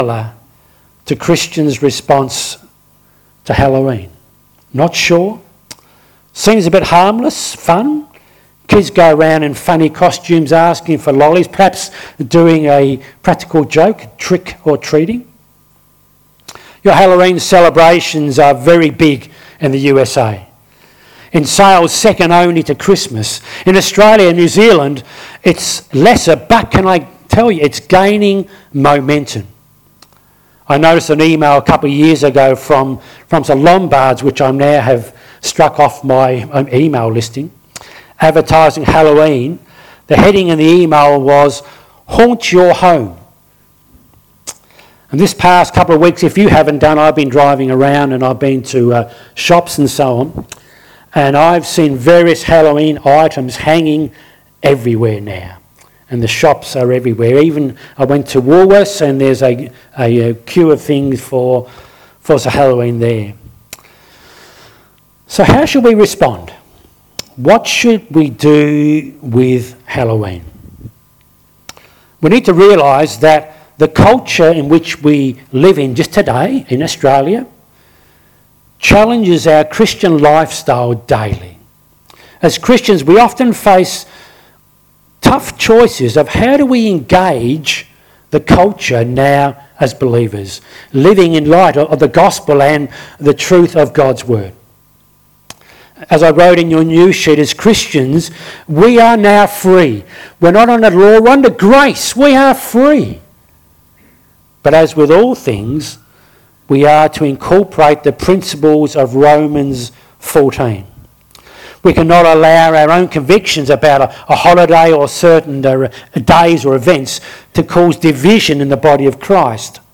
Lilydale Baptist Church 27th October 2024 10am Service